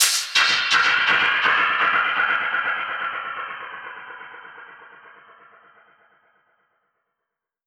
Index of /musicradar/dub-percussion-samples/125bpm
DPFX_PercHit_E_125-07.wav